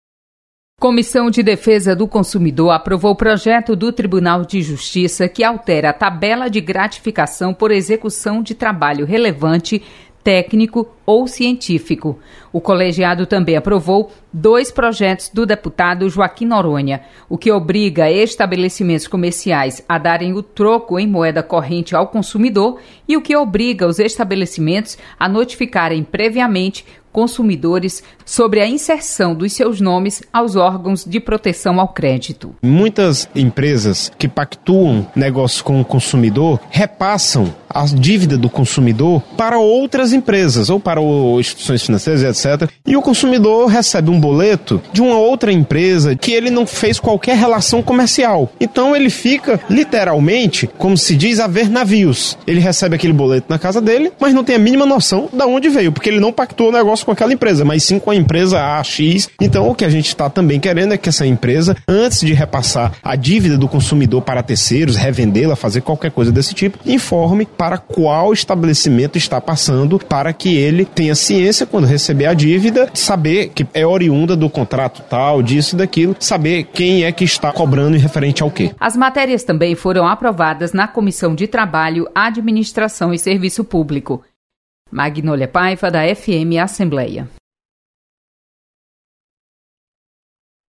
Acompanhe resumo das comissões técnicas permanentes da Assembleia Legislativa com a repórter